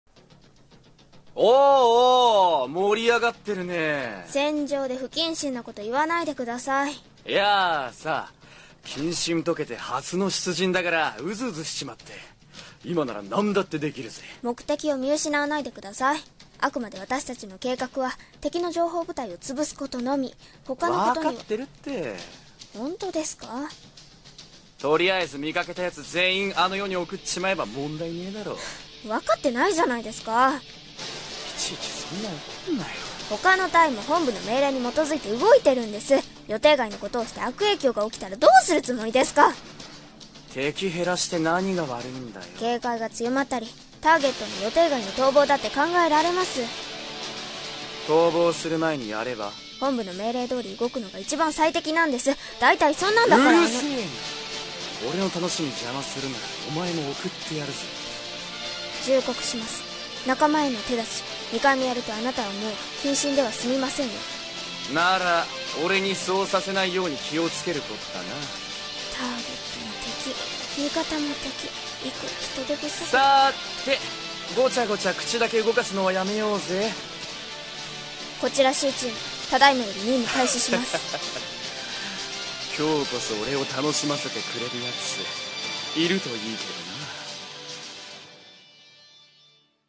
声劇『敵と任務』